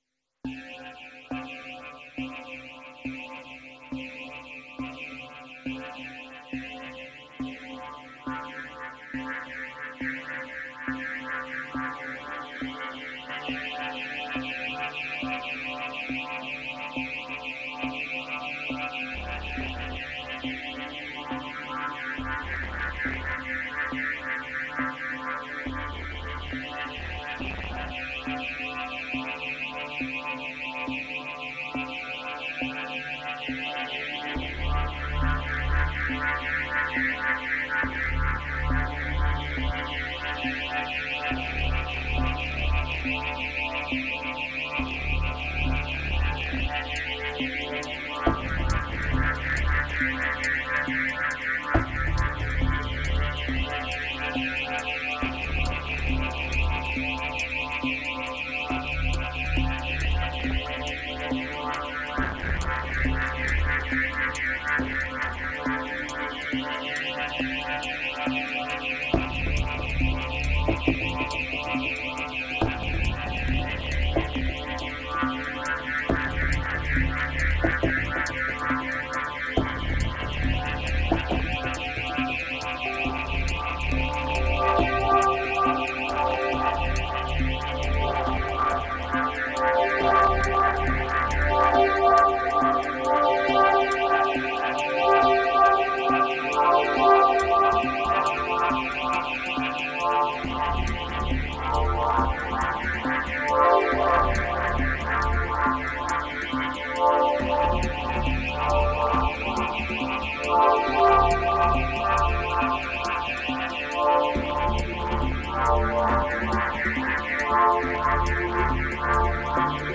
2937📈 - 77%🤔 - 69BPM🔊 - 2017-02-07📅 - 675🌟